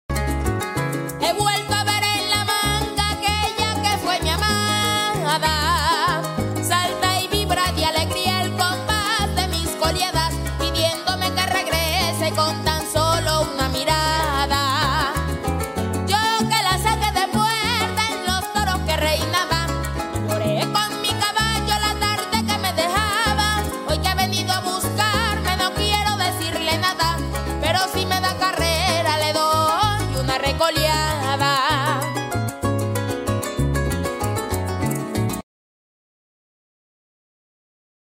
La mejor música llanera